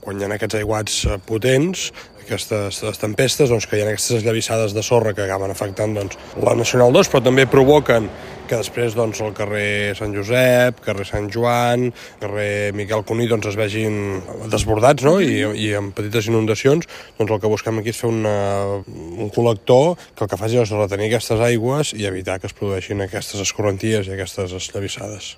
Ho explica l’alcalde Marc Buch en declaracions a Ràdio Calella TV.